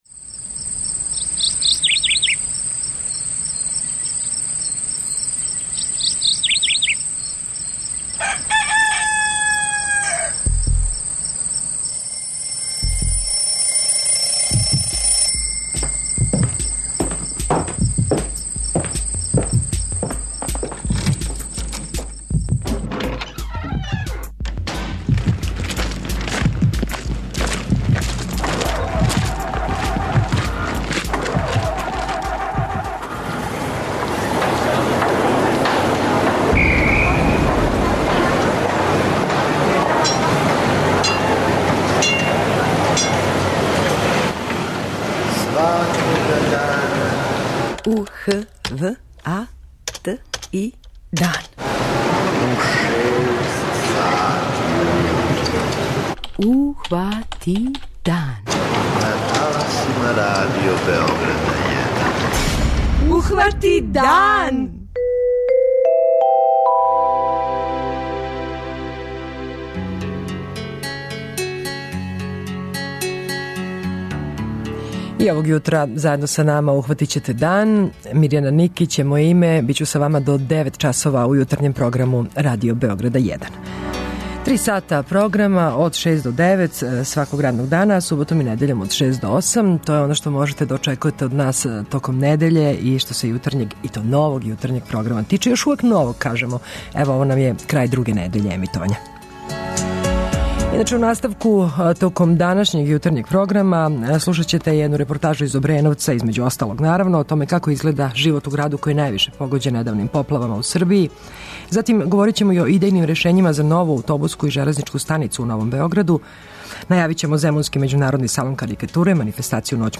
Репортажу из Обреновца: како изгледа живот у граду који је највише погођен недавним поплавама у Србији.
Идејна решења за нову аутобуску и железничку станицу у Новом Београду. Контакт програм "Питање јутра" - поводом најаве да ће најбољи тимови стручњака у Министарству здравља водити рачуна о свим областима медицине на подручју Србије, питамо слушаоце - како побољшати прилике у здравству, да ли је приоритет решавање листе чекања, и зашто су незадовољни и лекари и пацијенти.
преузми : 85.92 MB Ухвати дан Autor: Група аутора Јутарњи програм Радио Београда 1!